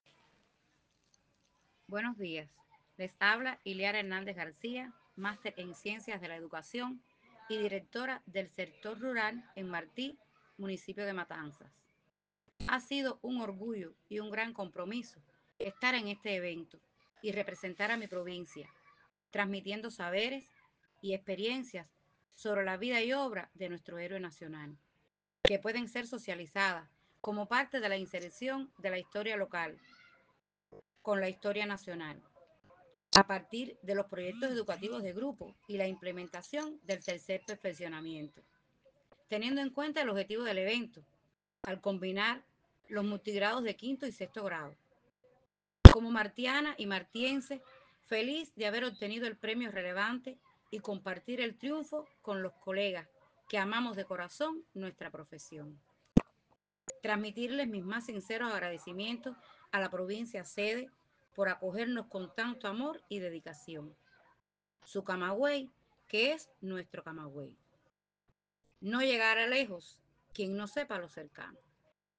La responsable del quehacer educacional en el sector rural de su localidad, expresó a Radio 26, por la aplicación WhatsApp, sus valoraciones sobre el encuentro y el trabajo que expuso para demostrar cómo se puede trasmitir de manera oportuna y creativa la historia local a través del currículo docente institucional y las actividades complementarias.